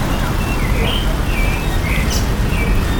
In deze pilot meten we geluidsoverlast met zes sensoren op het Marineterrein.
Vogels (mp3)
birds.mp3